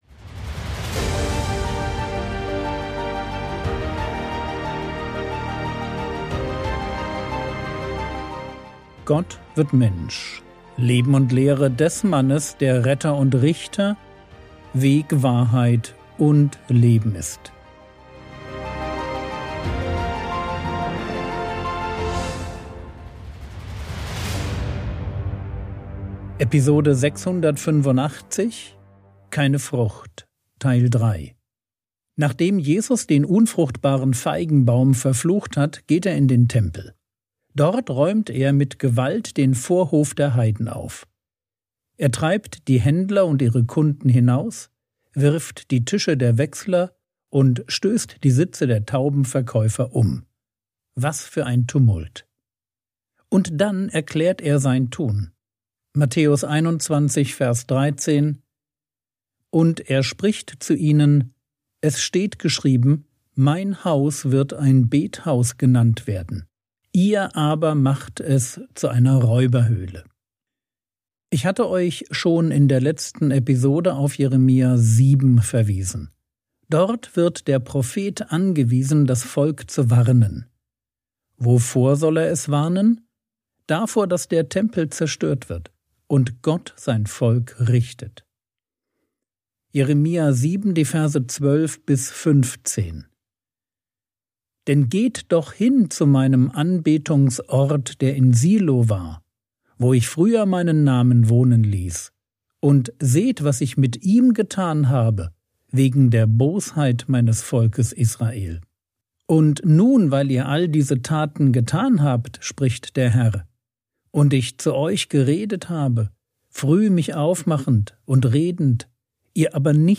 Episode 685 | Jesu Leben und Lehre ~ Frogwords Mini-Predigt Podcast